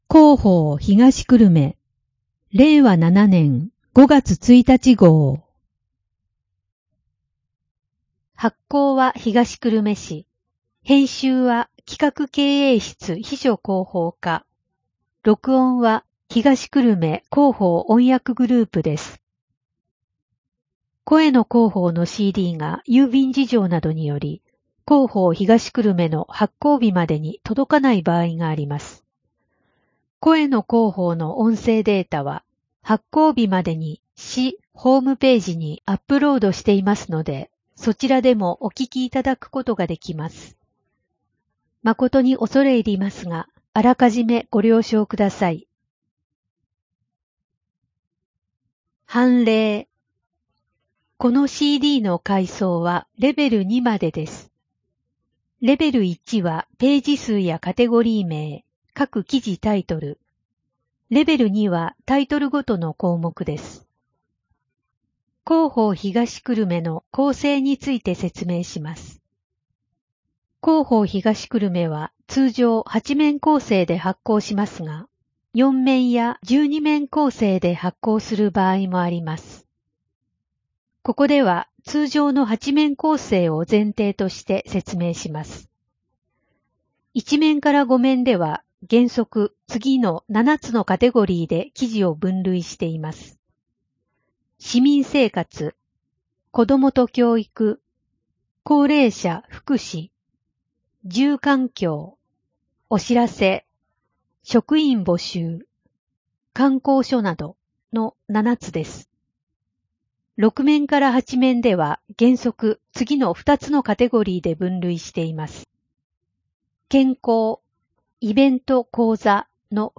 声の広報（令和7年5月1日号）